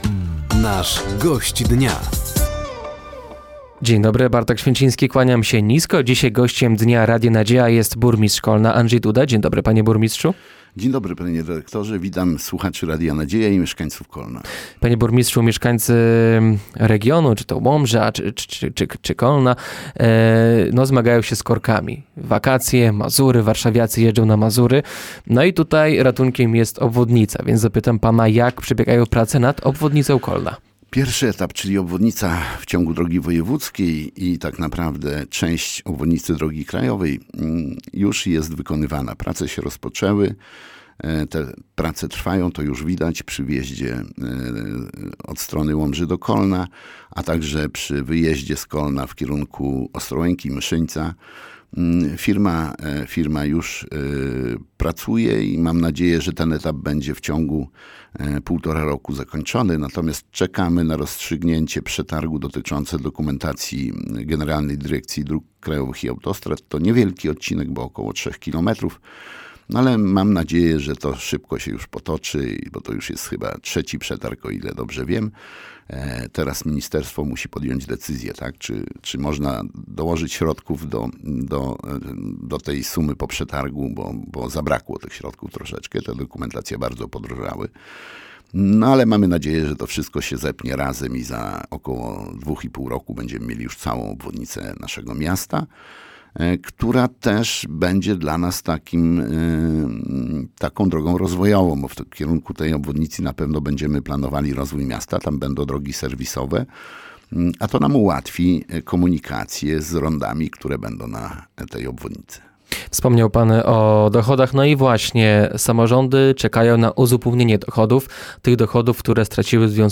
Gościem Dnia Radia Nadzieja był burmistrz Kolna Andrzej Duda. Tematem rozmowy była między innymi budowa obwodnicy Kolna, dochody miasta, inwestycje oraz przygotowania do przyszłorocznego jubileuszu 600-lecia nadania Kolnu praw miejskich.